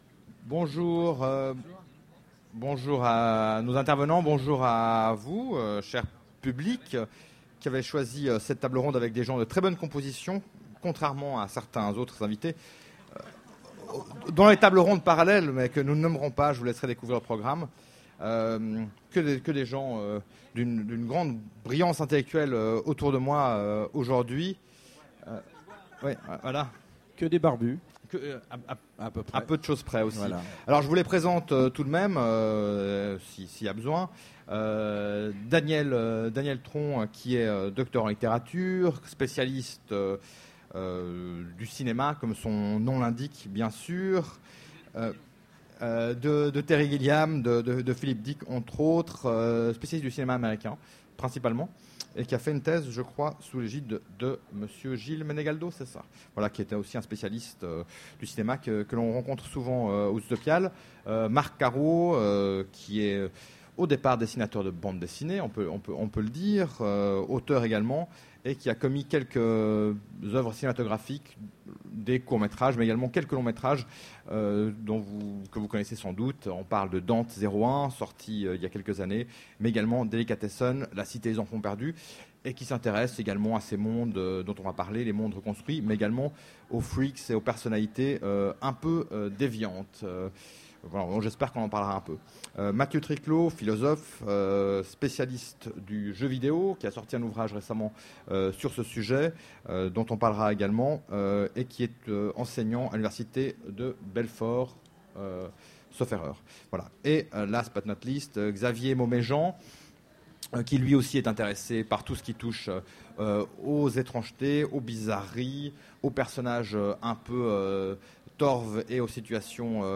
Utopiales 13 : Conférence Parcs d'attractions, malls et mondes factices